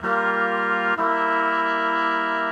Index of /musicradar/gangster-sting-samples/95bpm Loops
GS_MuteHorn_95-G2.wav